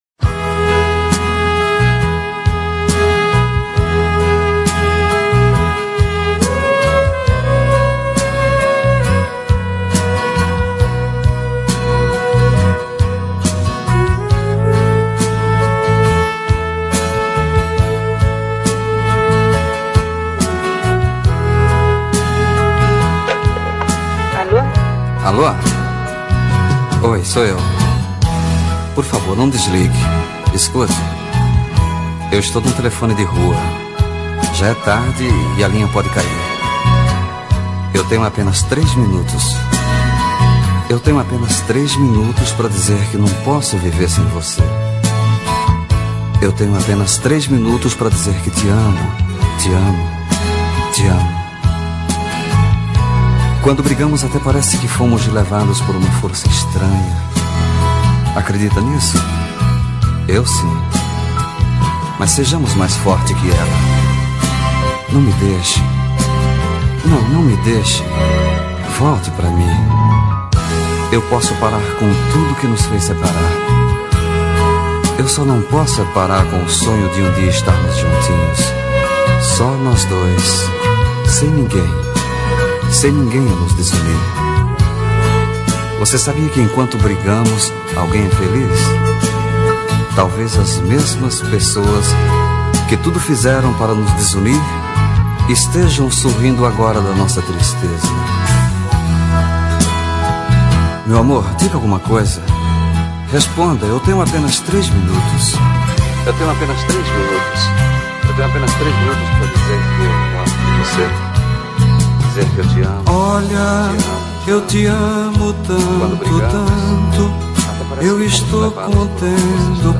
Bregas